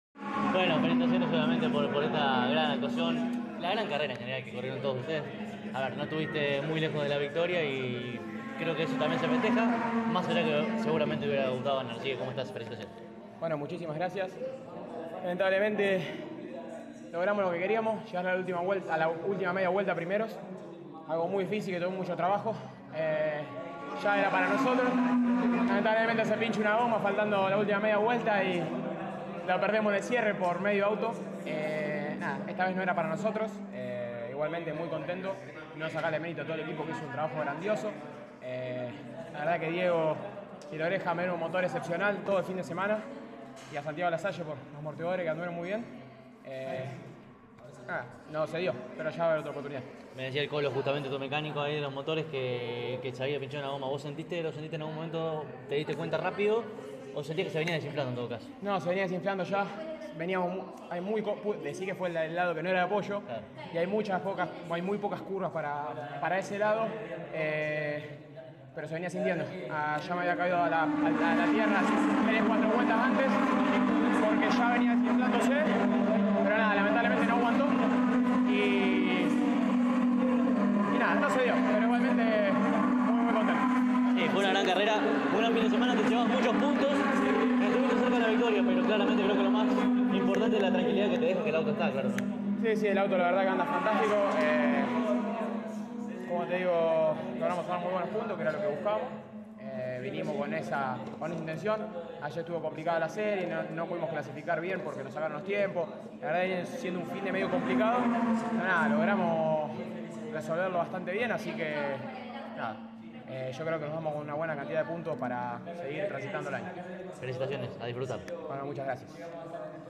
El TP disputó la segunda fecha de su calendario 2026 en el autódromo de Toay (provincia de La Pampa), y allí estuvo CÓRDOBA COMPETICIÓN.